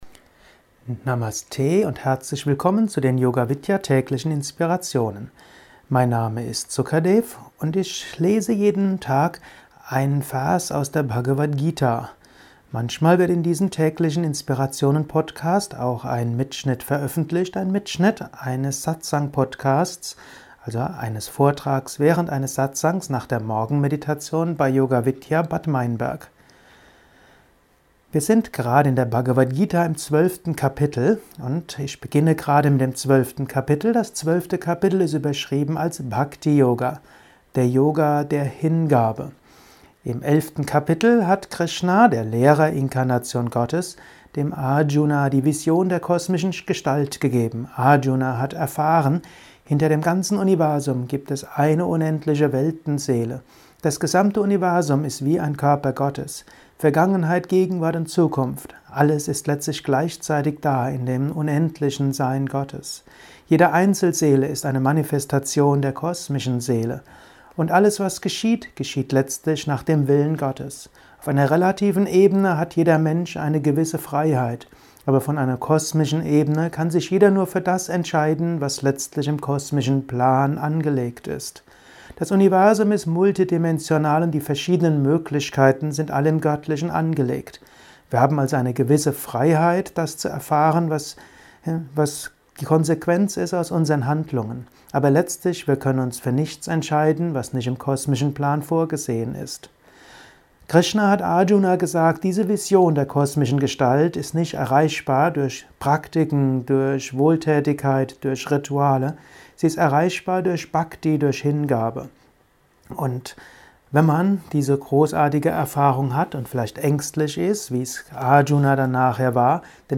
ist ein kurzer Kommentar als Inspiration für den heutigen Tag von